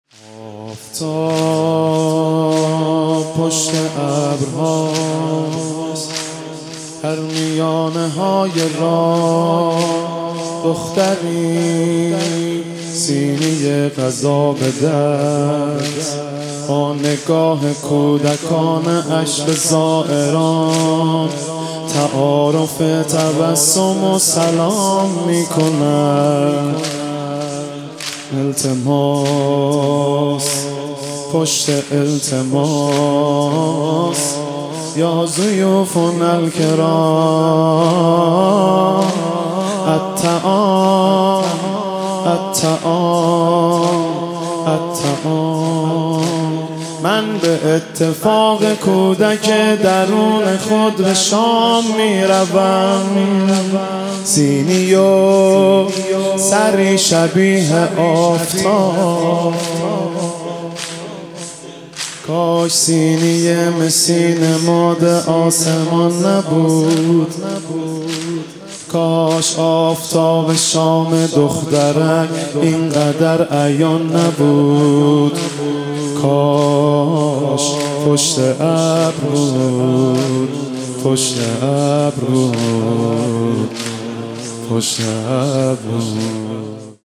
آفتاب پشت ابرها مداحی جدید مهدی رسولی شب سوم محرم 1400